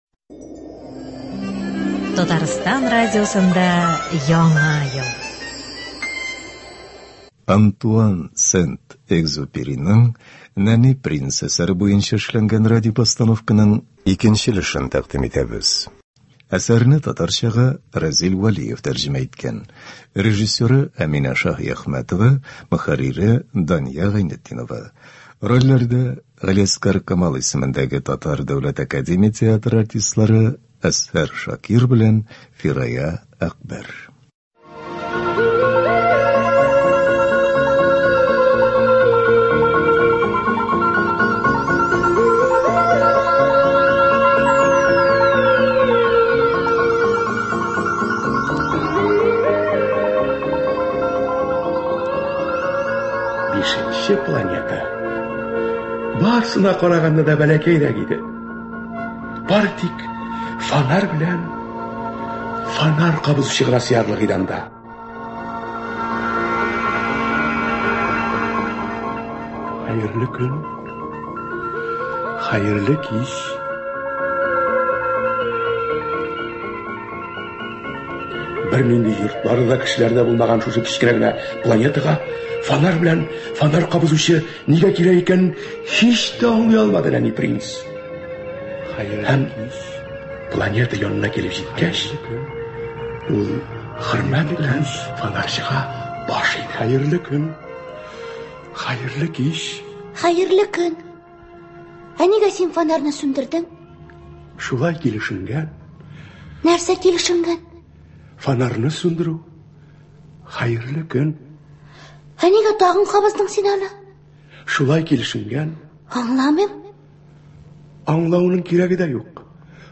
Радиопостановка.
Рольләрне Россиянең атказанган артисты Әзһәр Шакиров һәм Татарстанның халык артисты Фирая Әкбәрова башкара.